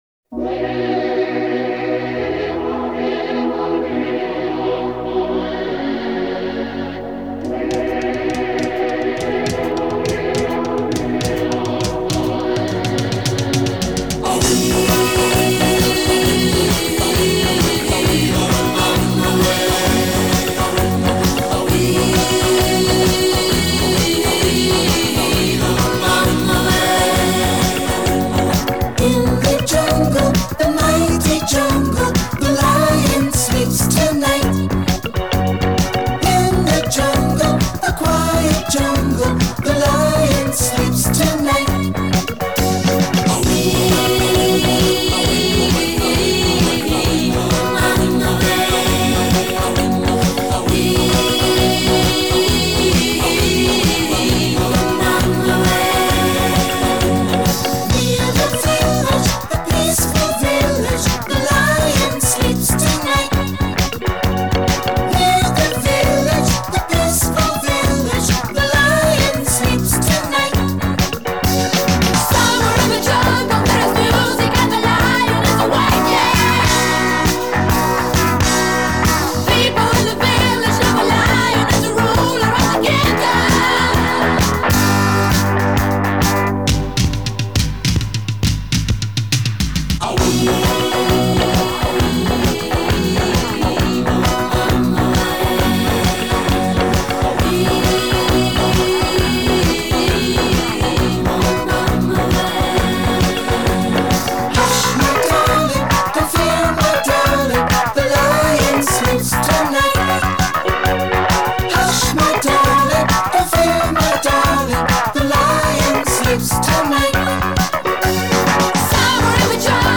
Disco Italo Disco